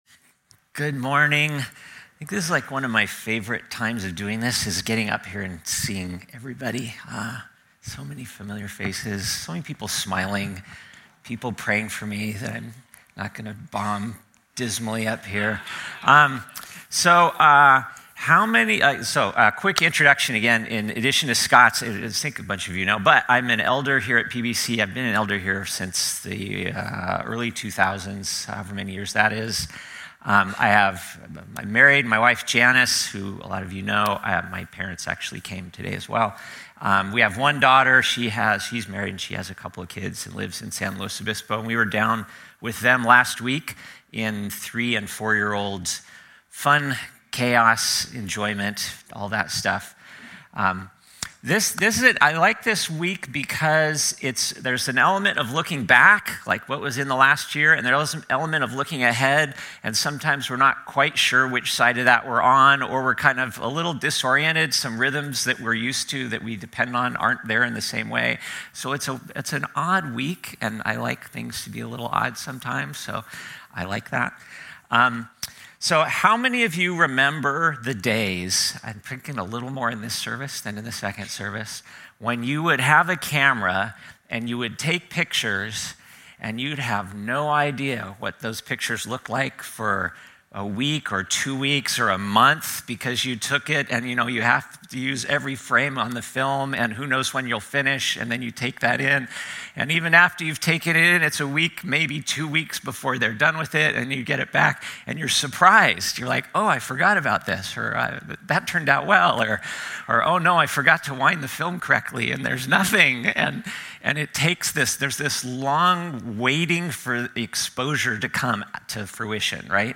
A message from the series "Luke." Before Jesus begins his earthly ministry, the stage is set by his cousin John.